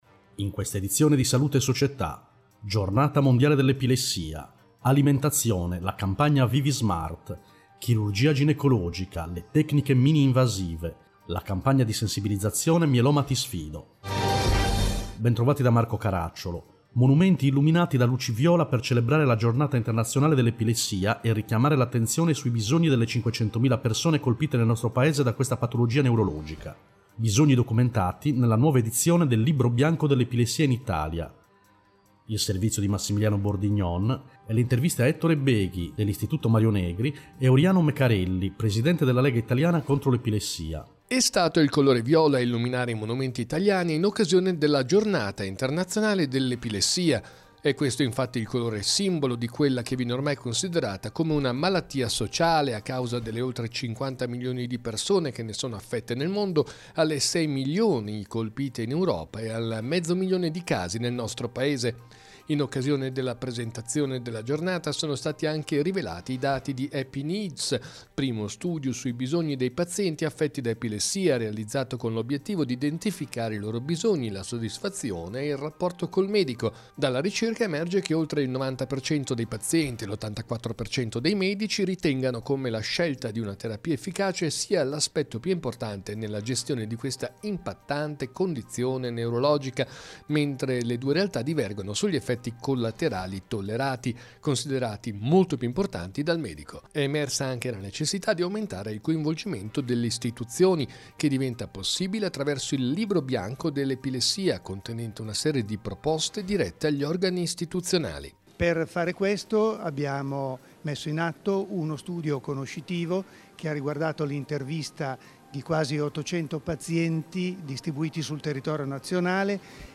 In questa edizione: 1. Epilessia, Giornata mondiale 2. Alimentazione, Campagna ViviSmart 3. Chirurgia ginecologica, Tecniche mininvasive 4. Mieloma Ti Sfido, Campagna di sensibilizzazione Interviste